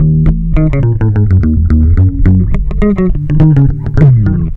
RI BASS 2 -L.wav